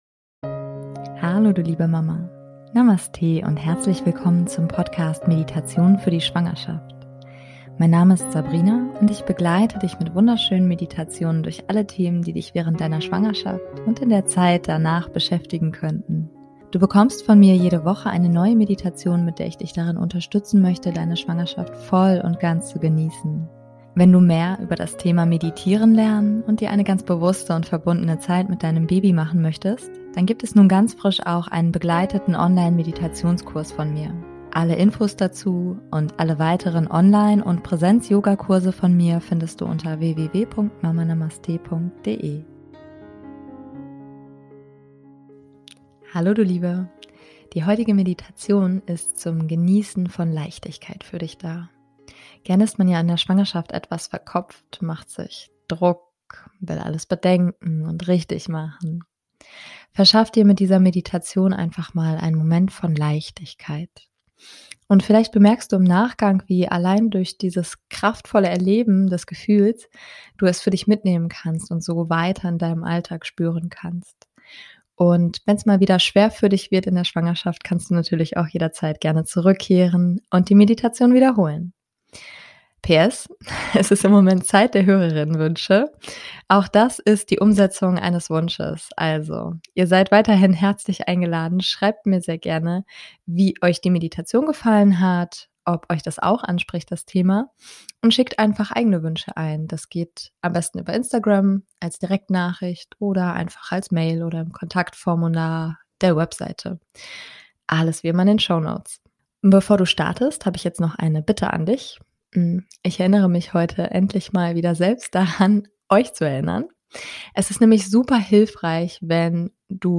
#092 - Meditation für Leichtigkeit in der Schwangerschaft ~ Meditationen für die Schwangerschaft und Geburt - mama.namaste Podcast